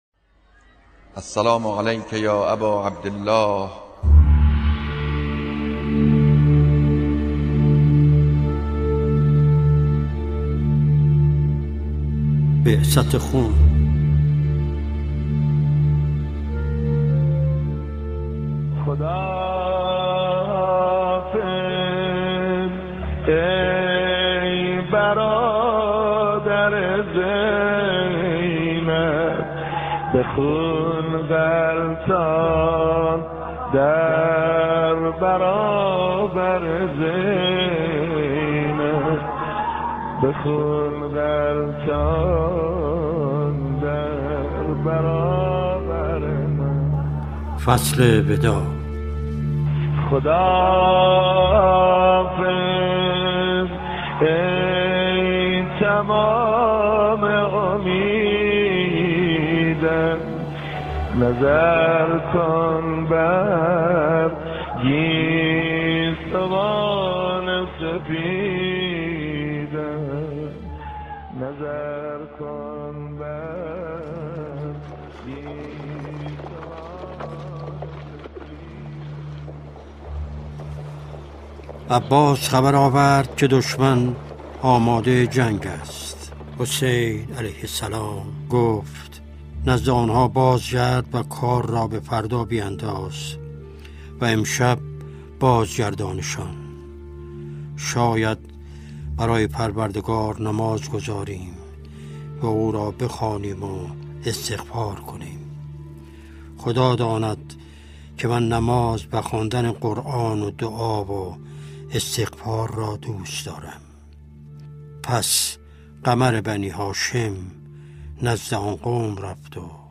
این مجلس عزاداری اینترنتی، شامل دو بخش «منبر و عزاداری» است. در بخش «منبر»، سخنان رهبر معظم انقلاب درباره بصیرت عوام و خواص و نقش آن در وقوع حوادث سال شصت و یکم هجری قمری، عبرت‌ها و تحلیل واقعه‌ی عاشورا پخش می‌شود.